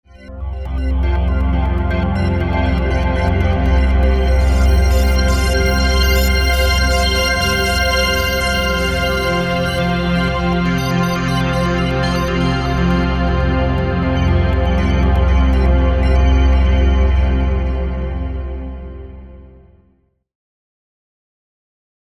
Synth pad based on fourths
spy6_Pad.mp3